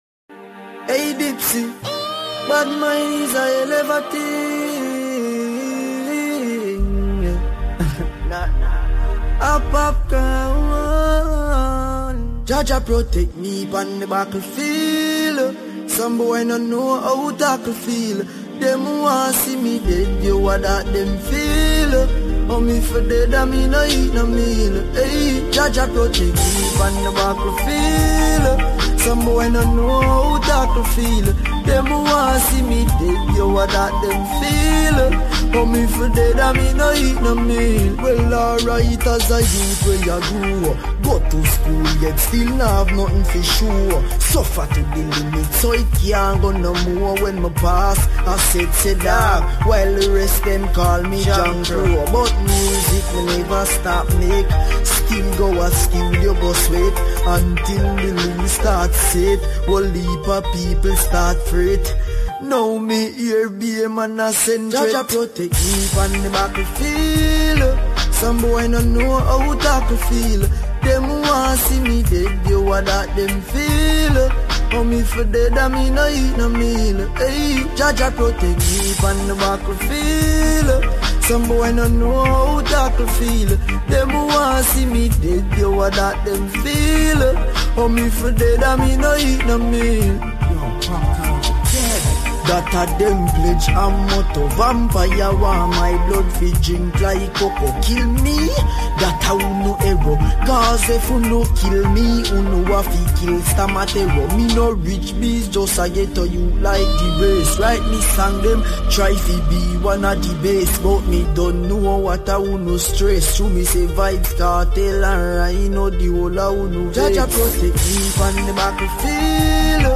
smooth transitions and thoughtful track choices
Genre: Dancehall
two-and-a-half-hour mix of dancehall hits